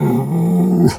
dog_2_growl_05.wav